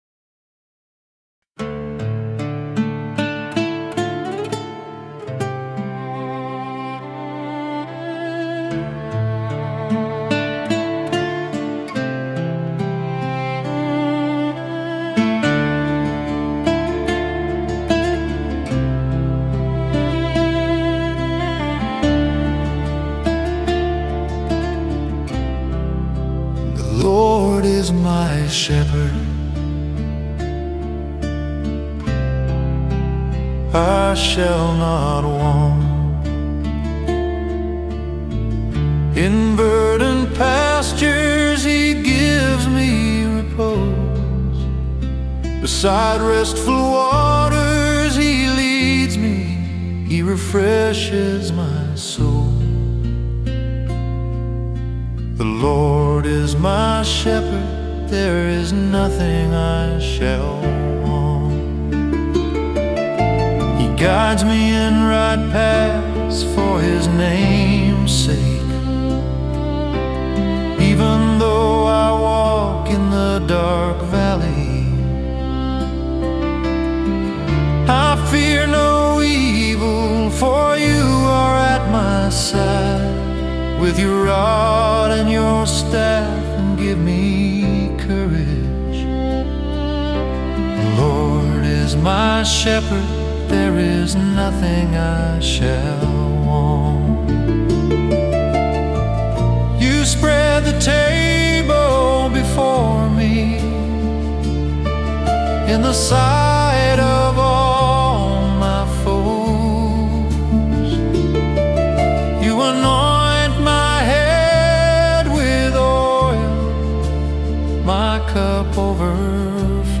Psalm setting